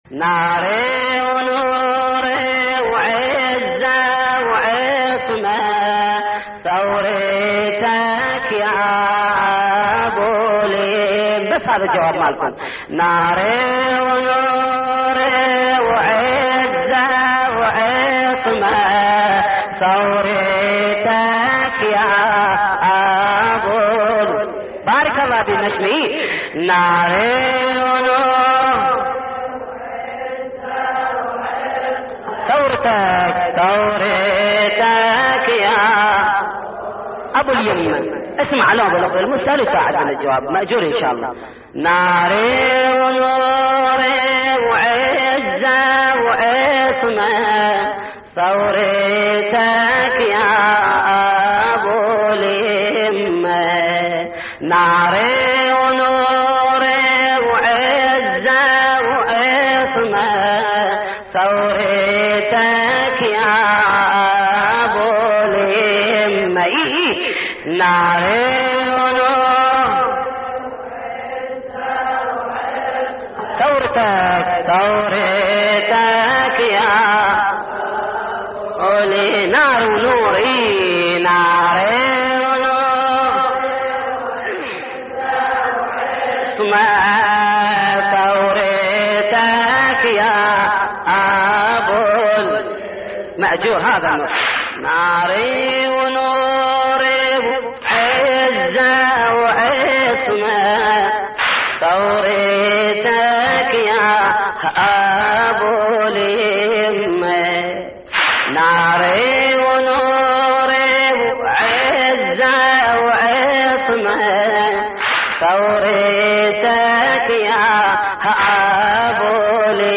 تحميل : نار ونور وعزة وعصمة ثورتك يا أبو اليمة / الرادود جليل الكربلائي / اللطميات الحسينية / موقع يا حسين